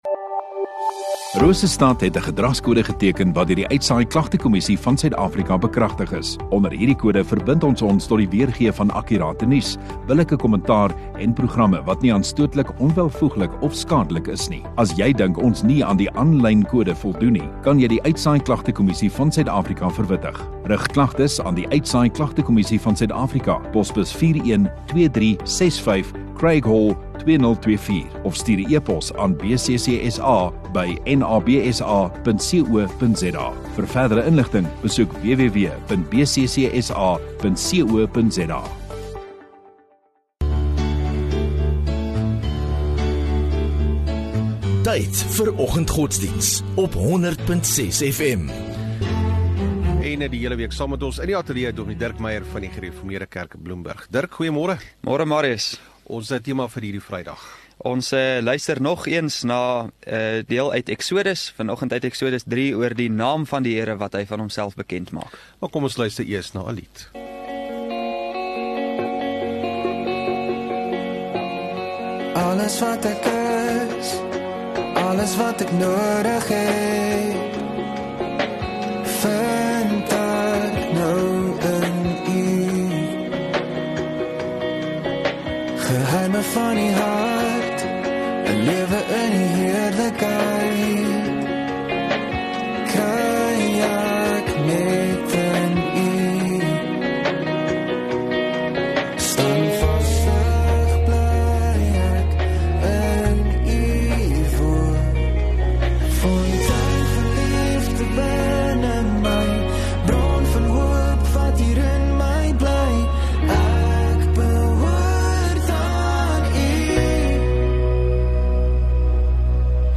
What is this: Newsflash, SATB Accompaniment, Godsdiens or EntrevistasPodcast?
Godsdiens